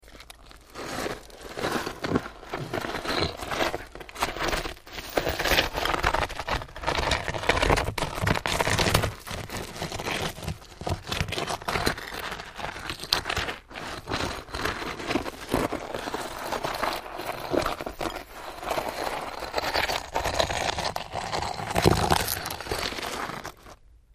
Scrape, Stone
StoneScrapesOnGrit PE442002
Stone Scrapes; On Gritty Stone And Gravel.